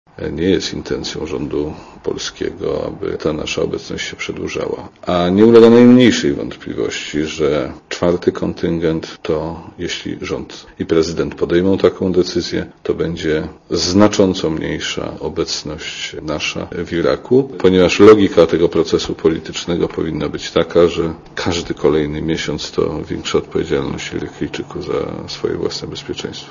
* Mówi minister Jerzy Szmajdziński*